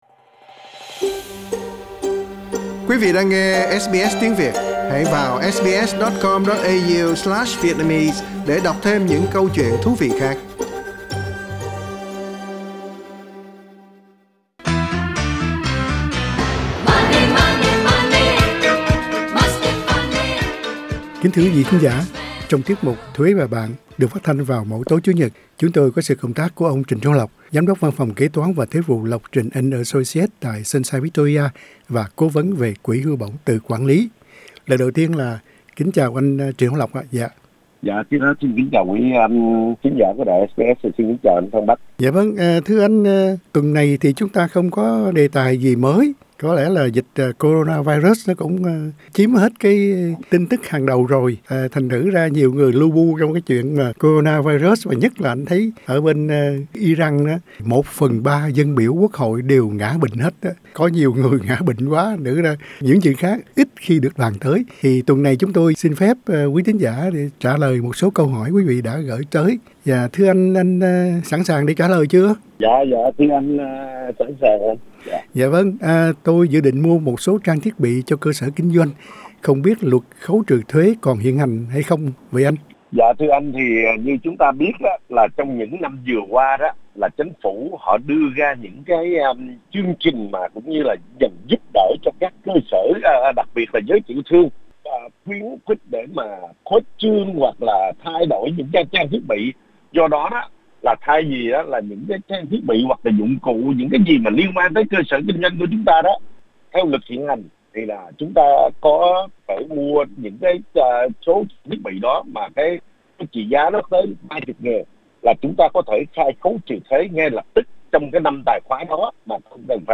Hôm nay chúng tôi dành trọn thời lượng phát thanh để trả lời các câu hỏi của quí thính giả. gởi đến .Tôi dự định mua một số trang thiết bị cho cơ sở kinh doanh, không biết luật khấu trừ thuế còn áp dụng không vậy anh?